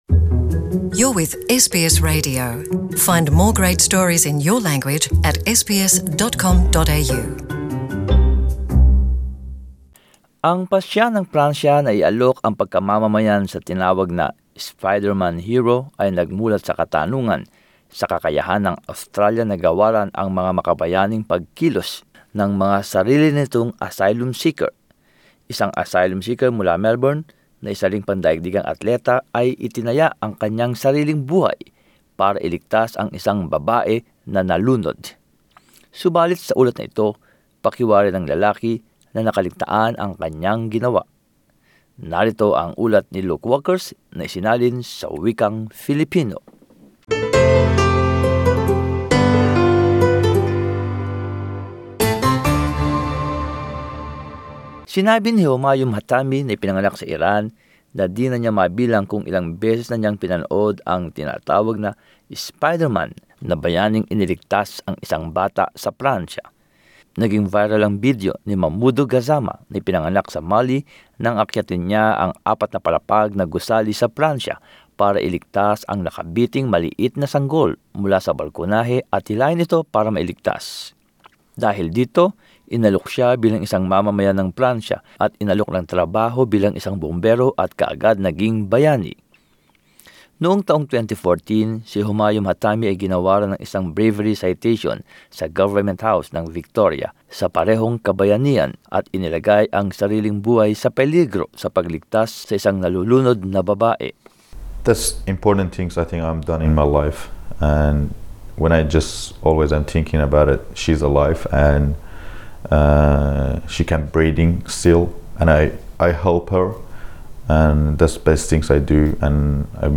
One Melbourne-based asylum seeker, also a world-class athlete, risked his life to save a woman from drowning. But as this report shows, the man feels what he did has been overlooked.